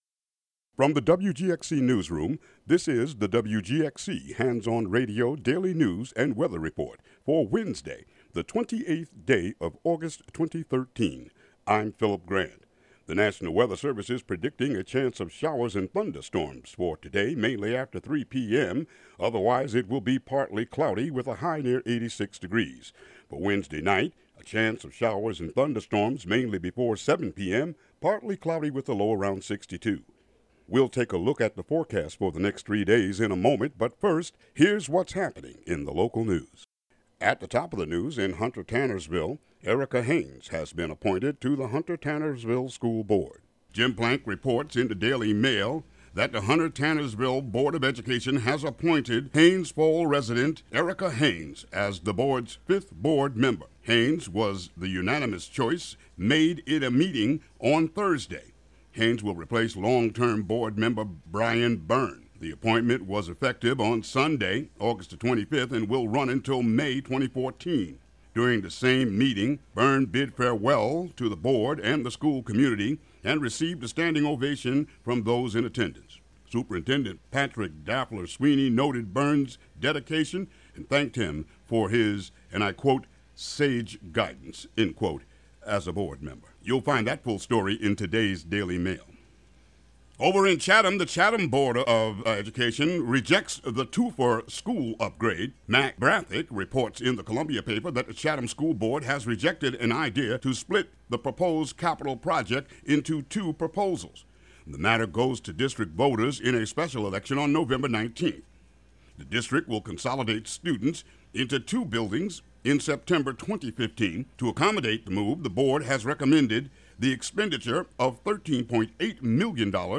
WGXC News and weather for Wednesday, August 28, 2013.